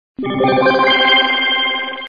Мелодії і звуки для СМС повідомлень